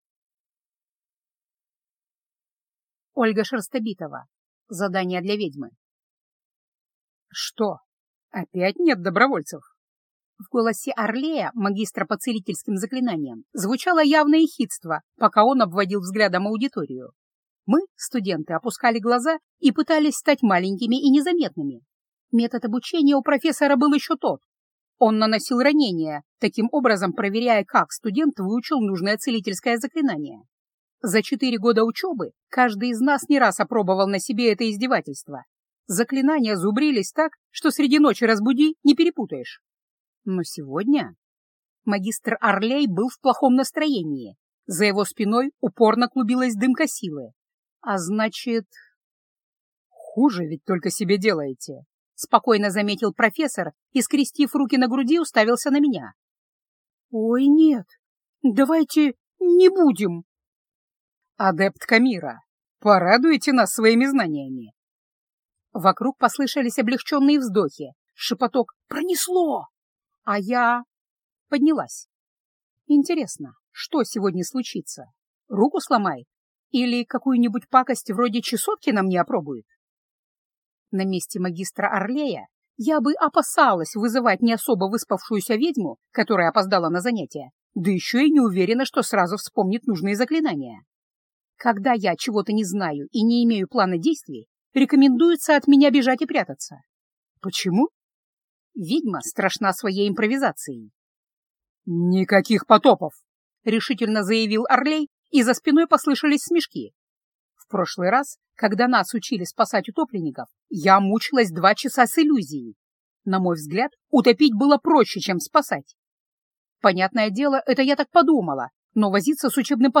Аудиокнига Задание для ведьмы | Библиотека аудиокниг